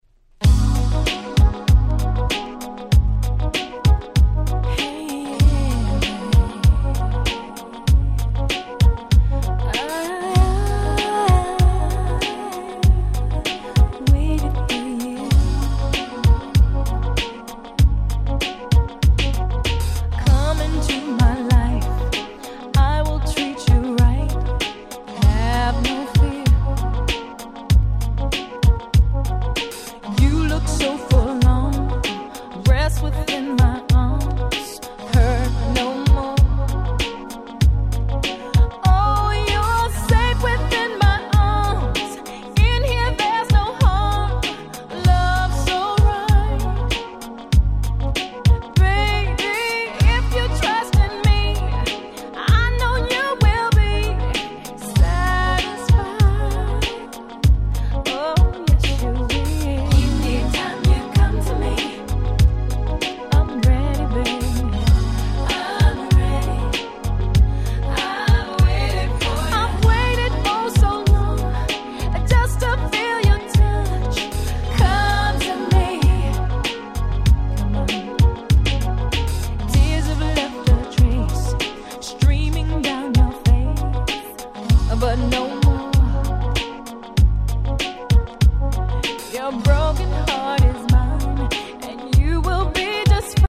97' Nice R&B !!
キャッチー系